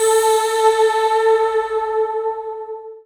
voiTTE64003voicesyn-A.wav